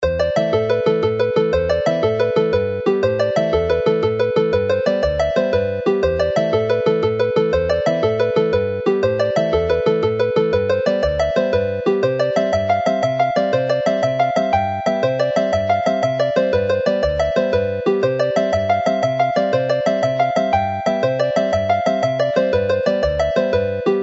The set concludes with the rip-roaring slip jig Neidod Twm Bach (little Tom's prank) from the excellent collection of 100 dance tunes in the second book of tunes collected from the National Library archives by Robin Huw Bowen and published by the Welsh Folk Dance Society, Cadw Twmpath.